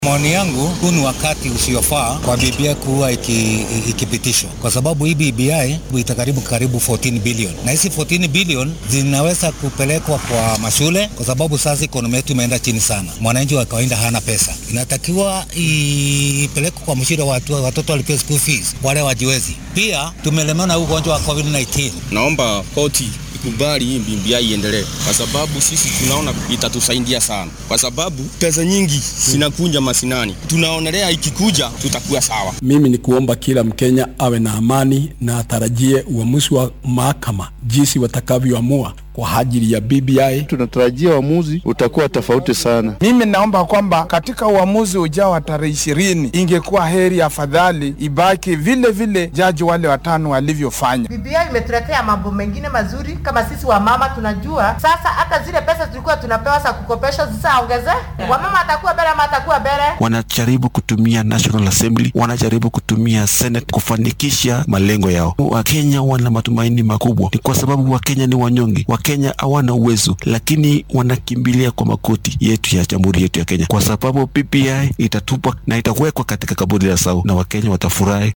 Waa sidee rajada kenyaanka ku nool qaybaha kale ee dalka , waa kuwan qaar ka mid ah oo warbaahinta la hadlay.